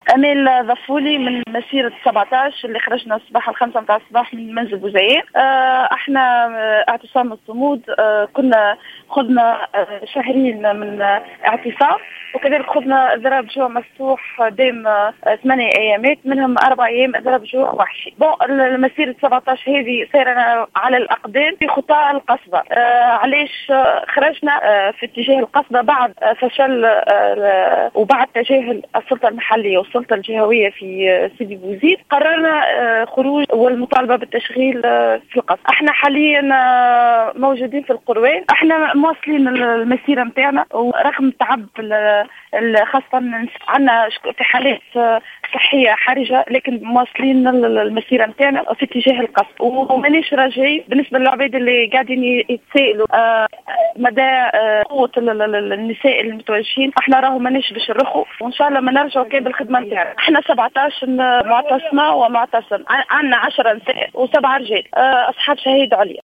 إحدى المشاركات، في تصريح للجوهرة اف ام